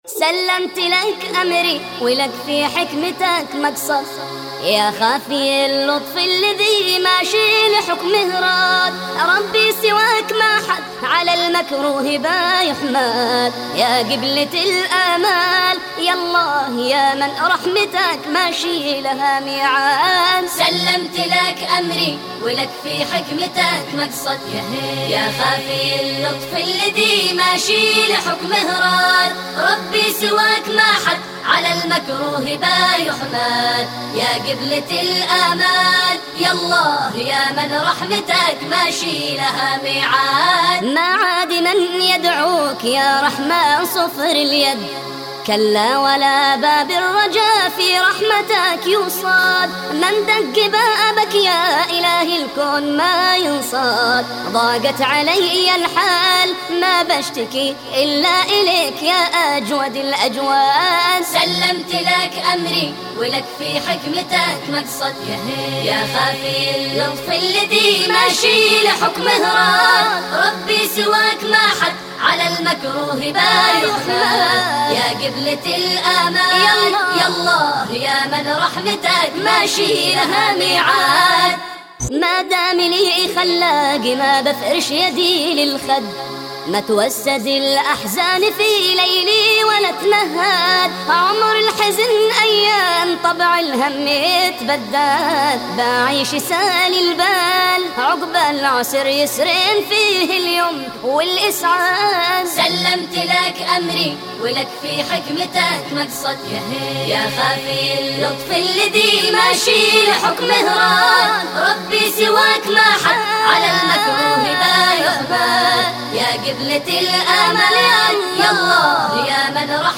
انشاد